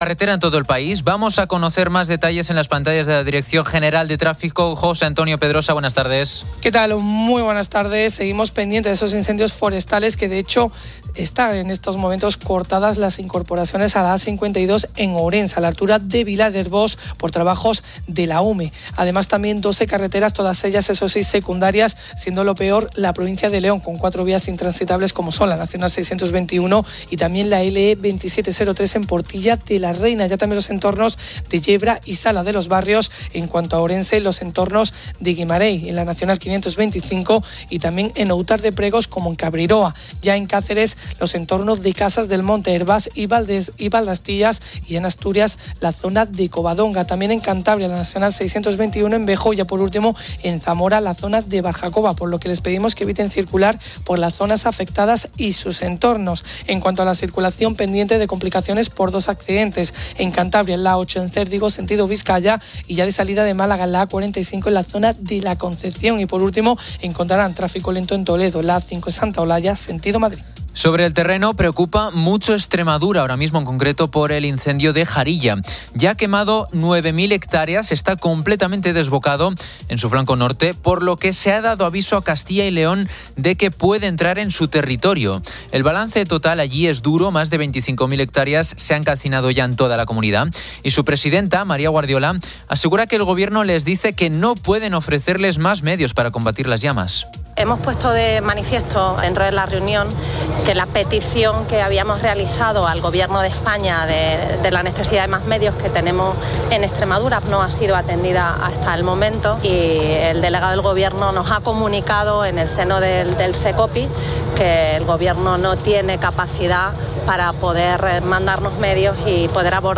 Fin de Semana 12:00H | 17 AGO 2025 | Fin de Semana En el estudio entrevistamos a Efecto Pasillo que nos cuentan sobre sus nuevos proyectos. Ronda por las zonas más afectadas por los incendios: Galicia, Castilla y León y Extremadura.